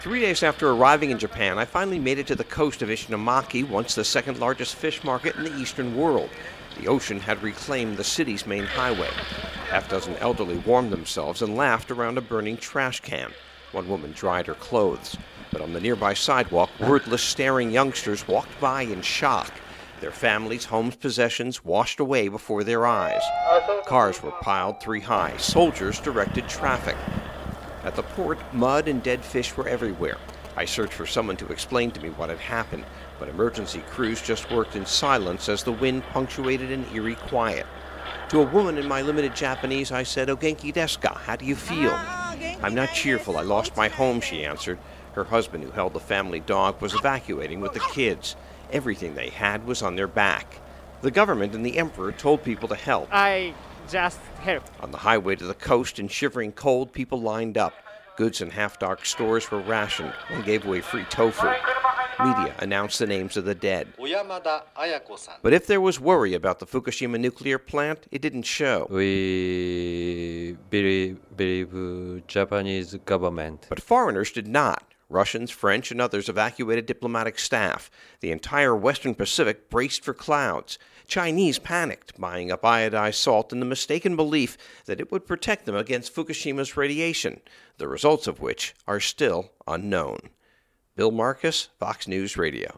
Below, the year-in-review piece summing up the entire event in one minute, thirty-two seconds.